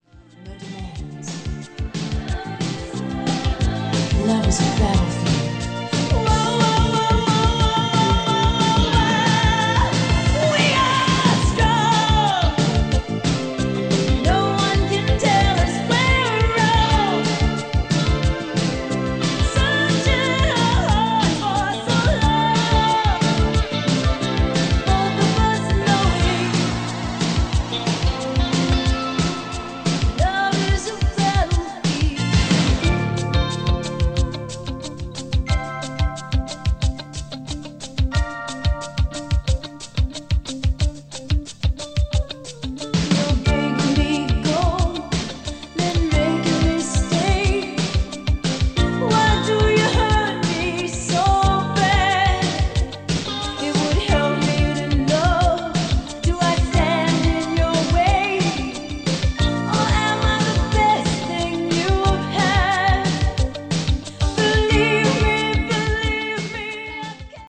We got Dolby B noise reduction on board as well as a memory function.
Below is a test recording made with the RA80C and played back by it: